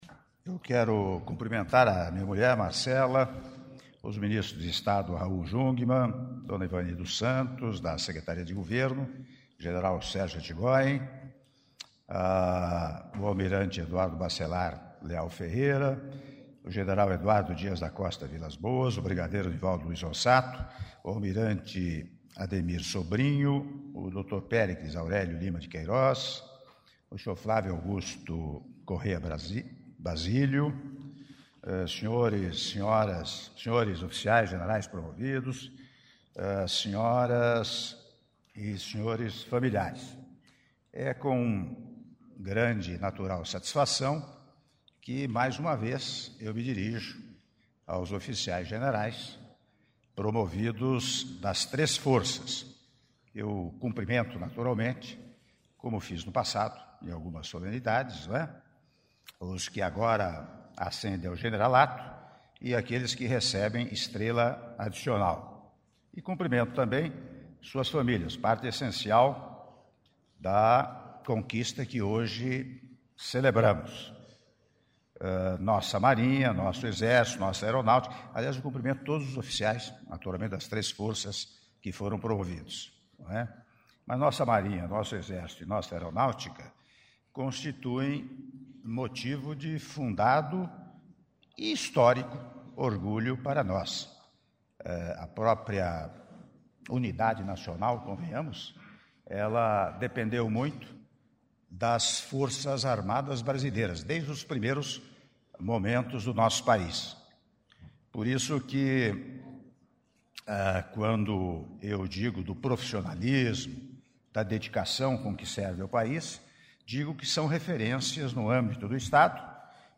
Áudio do discurso do presidente da República, Michel Temer, durante solenidade de apresentação de Oficiais-Generais promovidos- Brasília/DF (06min46s)